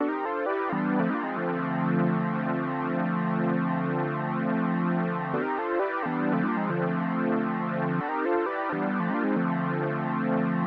描述：模糊的合成器垫
Tag: 94 bpm Hip Hop Loops Pad Loops 1.72 MB wav Key : Unknown